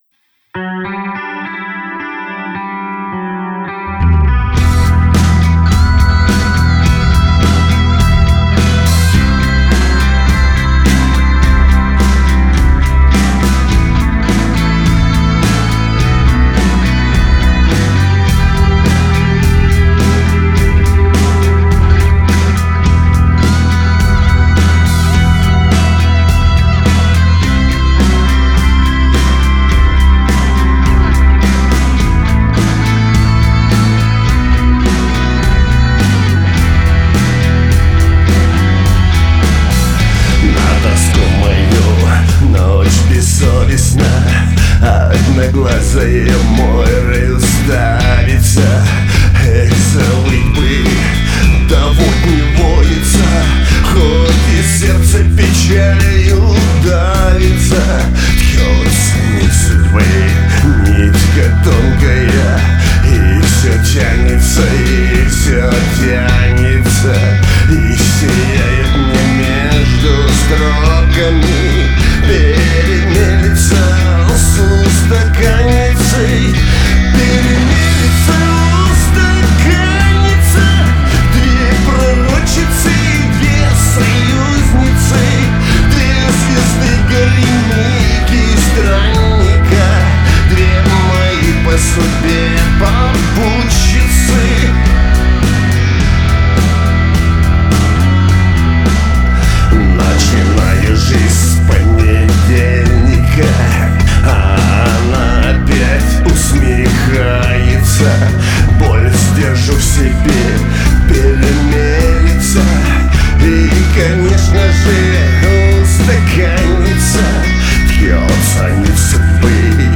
гитары